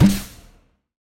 etfx_shoot_gas2.wav